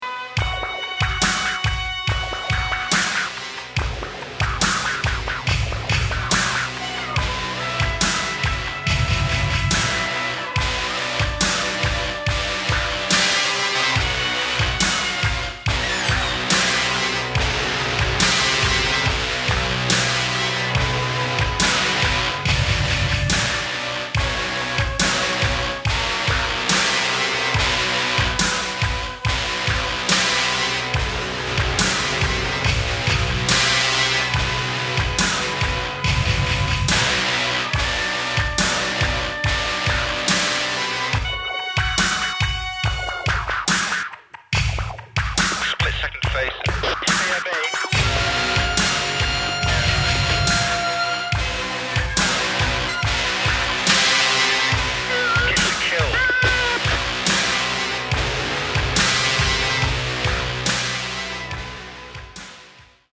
Dark, chaotic, perverse, and innovative
electronic and esoteric music
Wildly eclectic with regards to musical elements